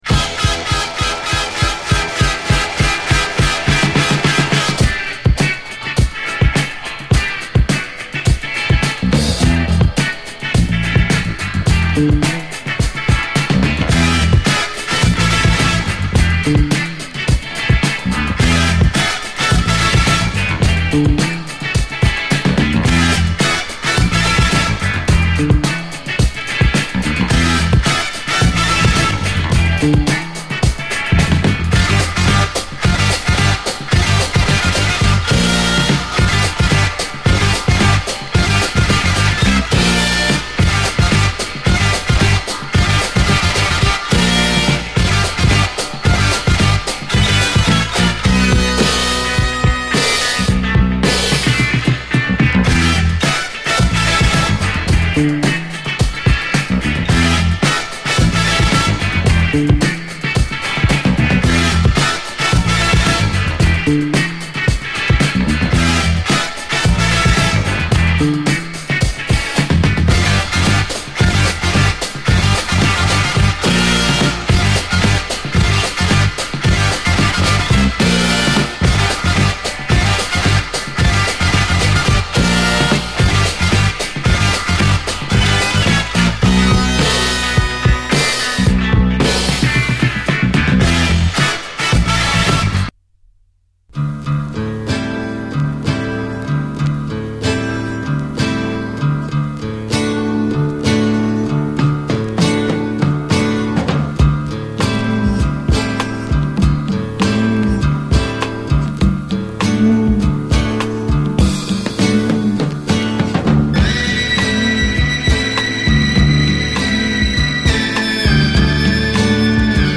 SOUL, 70's～ SOUL, 7INCH
サイケデリック・ファンク・クラシック